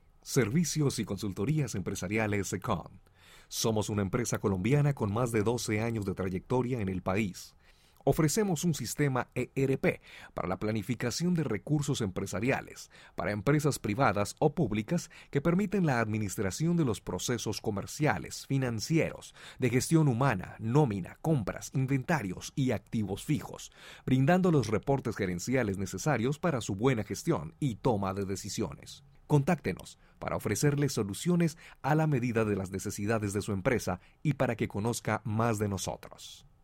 Una voz cálida y profesional
kolumbianisch
Sprechprobe: Sonstiges (Muttersprache):